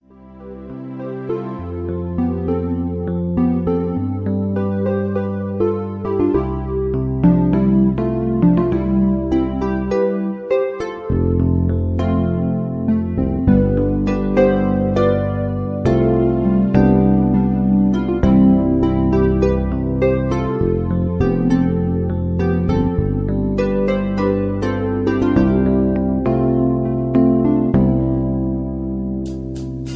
Just Plain & Simply "GREAT MUSIC" (No Lyrics).
mp3 backing tracks